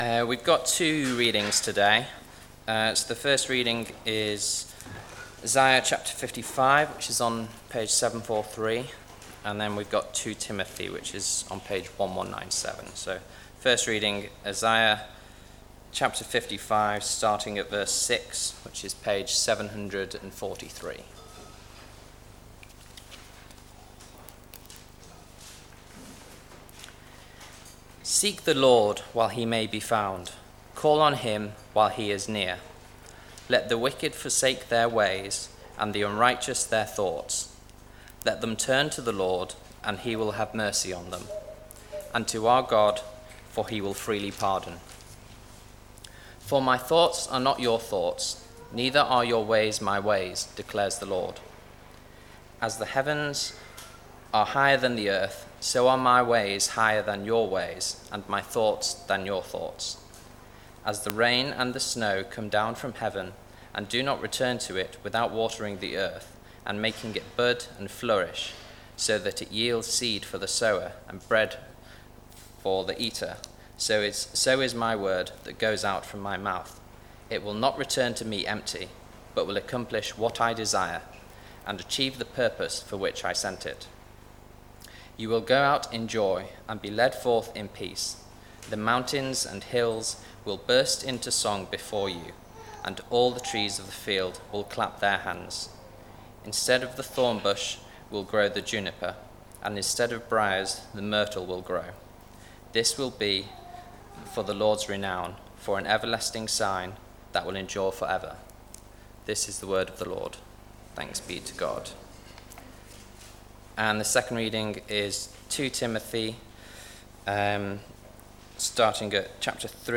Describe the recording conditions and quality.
Checking our foundations Passage: 2 Timothy 3:10-17 Service Type: Weekly Service at 4pm Topics